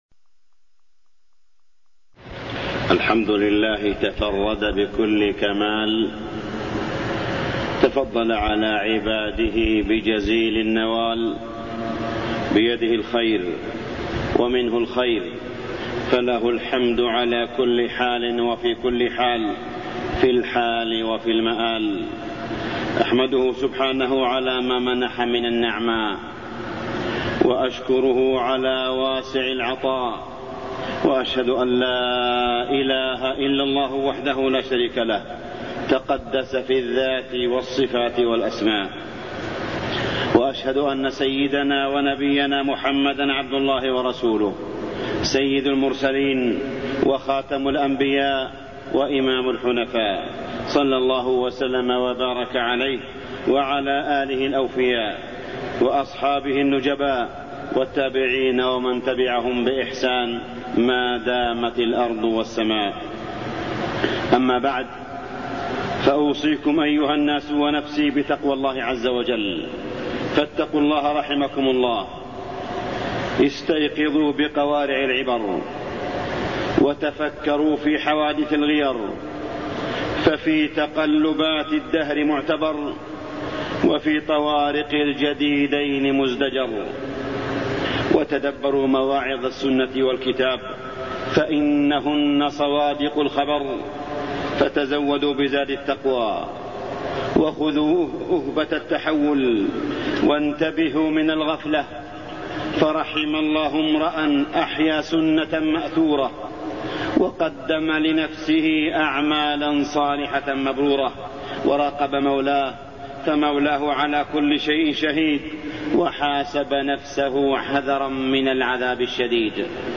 تاريخ النشر ١٠ جمادى الآخرة ١٤٢١ هـ المكان: المسجد الحرام الشيخ: معالي الشيخ أ.د. صالح بن عبدالله بن حميد معالي الشيخ أ.د. صالح بن عبدالله بن حميد الأمن وحوادث المرور